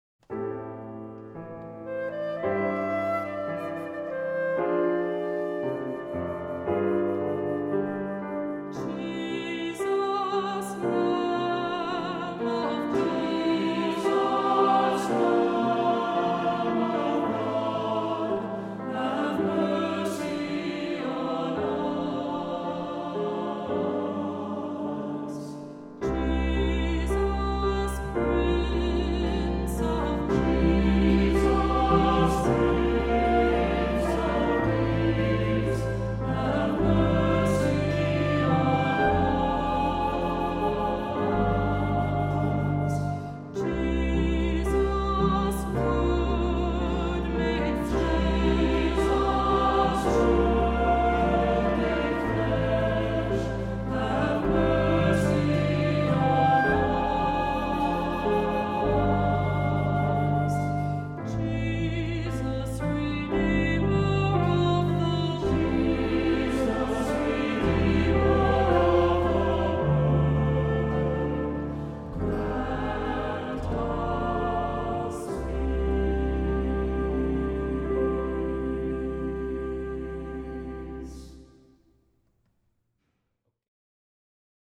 Voicing: Two-part mixed; Cantor; Assembly